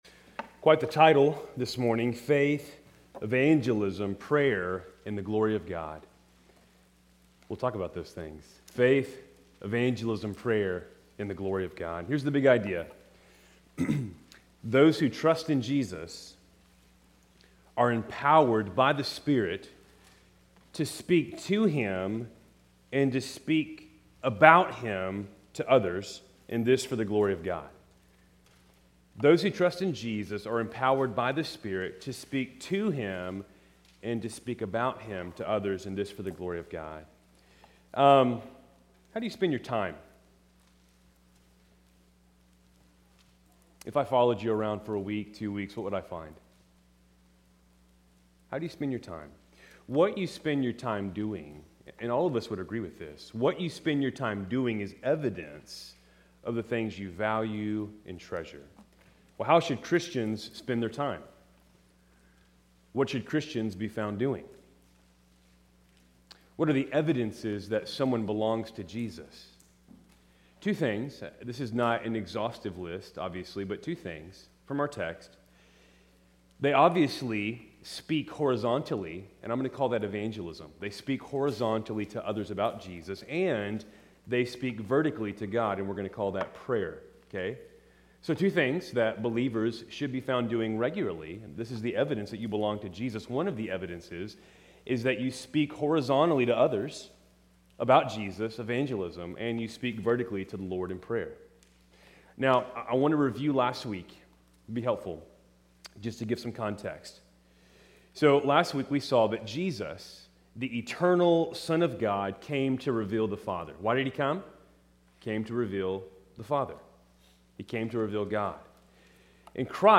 Keltys Worship Service, July 27, 2025